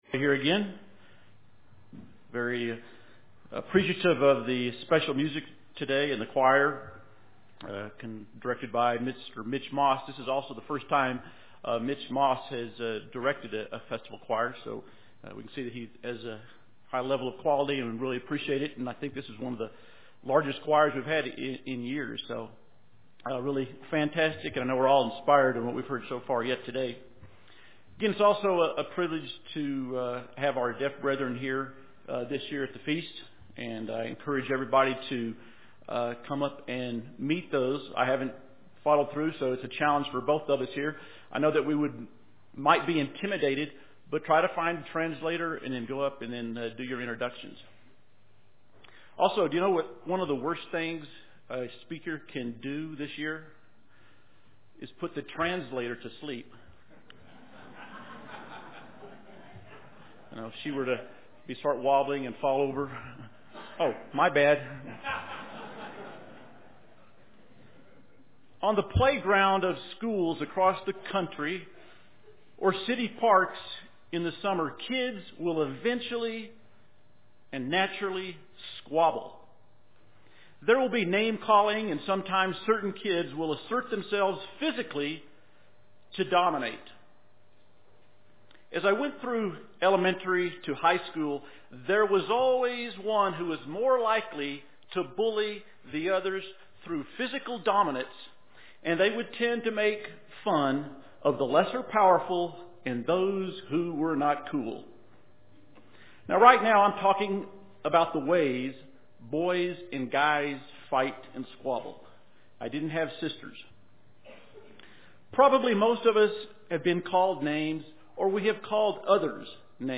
This sermon was given at the Steamboat Springs, Colorado 2013 Feast site.